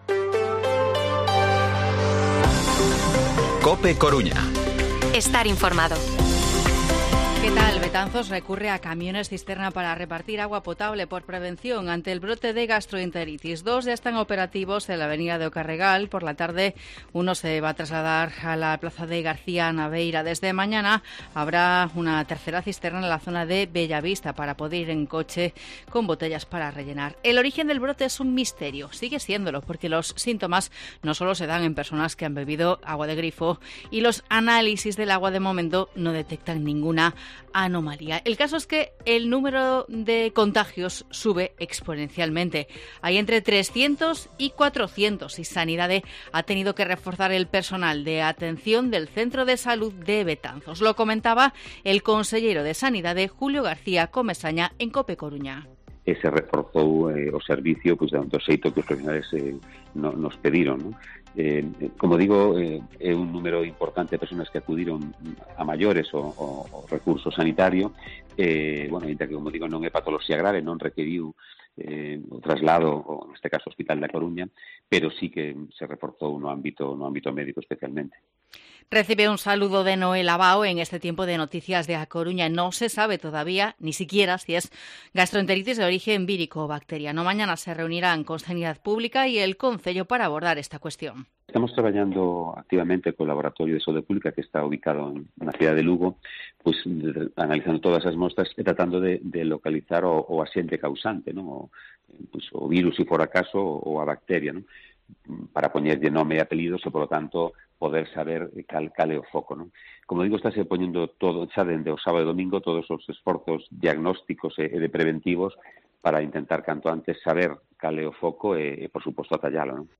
Informativo Mediodía COPE Coruña jueves, 1 de junio de 2023 14:20-14:30